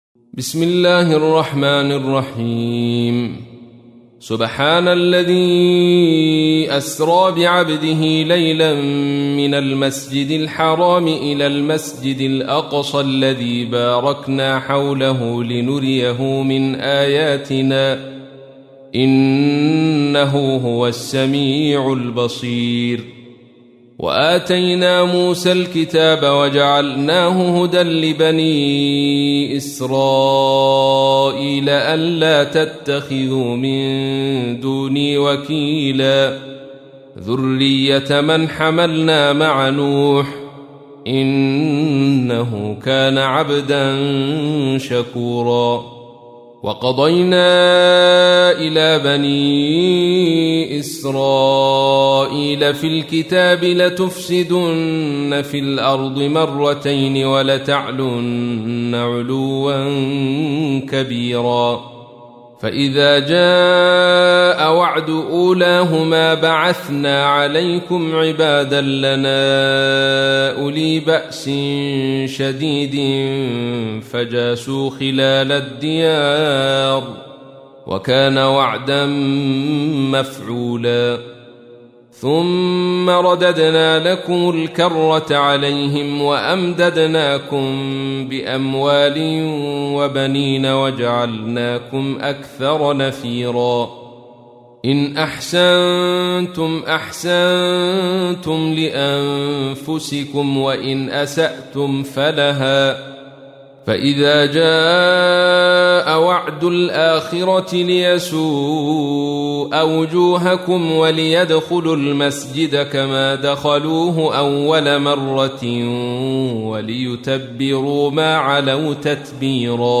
تحميل : 17. سورة الإسراء / القارئ عبد الرشيد صوفي / القرآن الكريم / موقع يا حسين